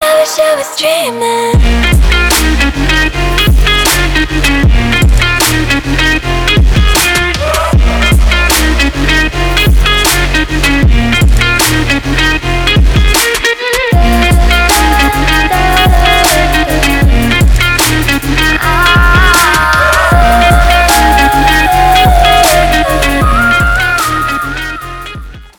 • Качество: 320, Stereo
красивые
женский вокал
Electronic
Trap
future bass